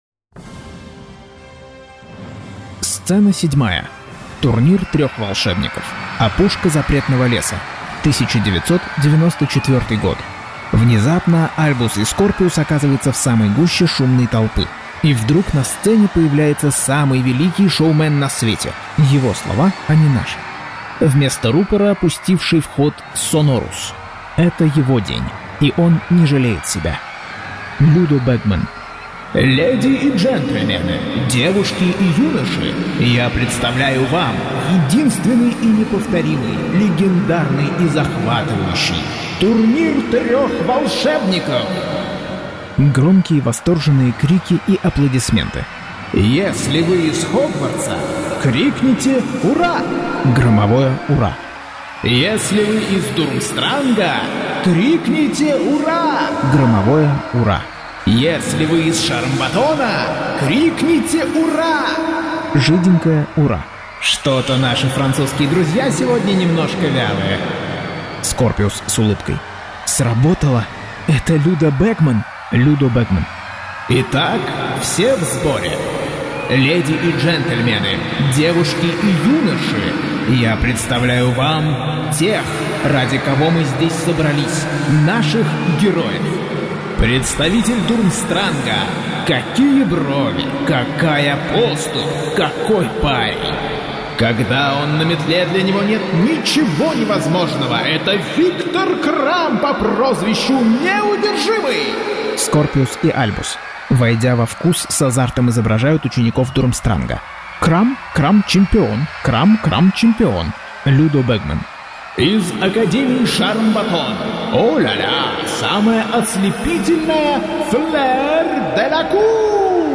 Аудиокнига Гарри Поттер и проклятое дитя. Часть 19.